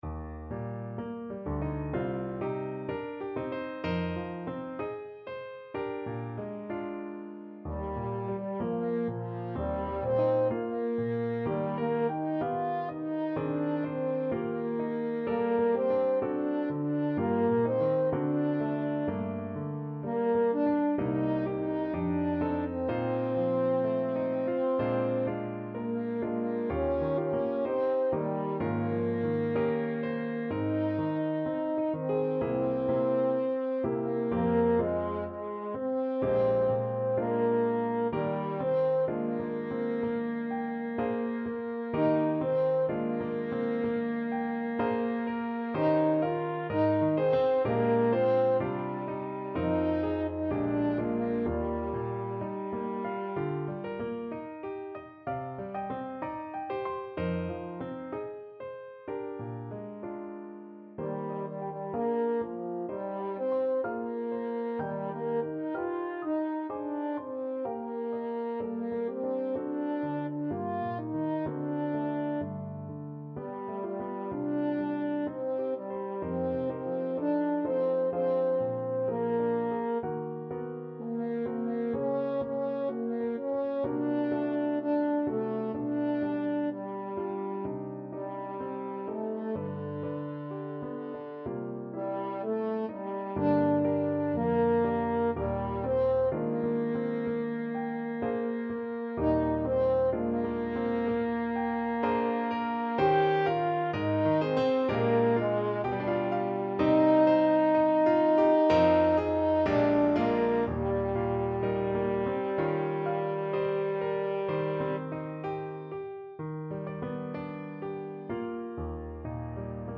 4/4 (View more 4/4 Music)
D4-G5
Classical (View more Classical French Horn Music)